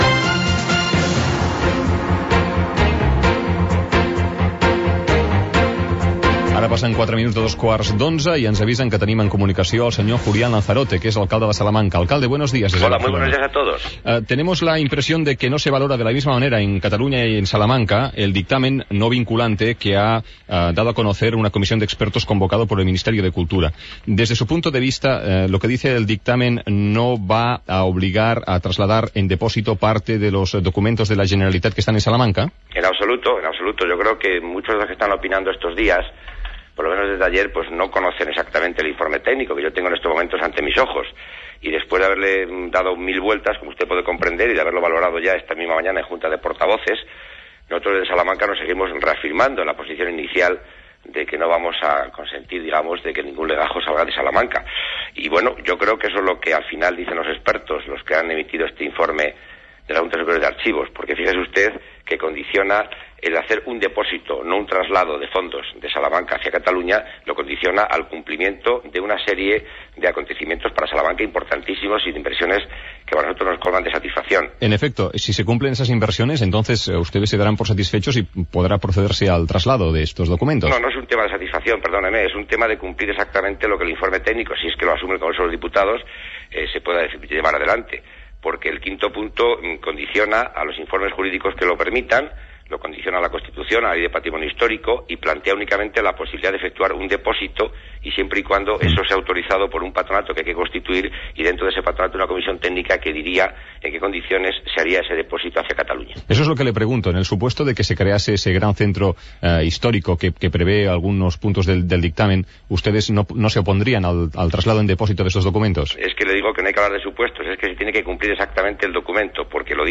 Entrevista l'alcalde de Salamanca, Julián Lanzarote, amb motiu del dictamen de la Junta Superior d'Arxius, favorable al retorn a Catalunya dels documents de l'Arxiu Històric Nacional
Info-entreteniment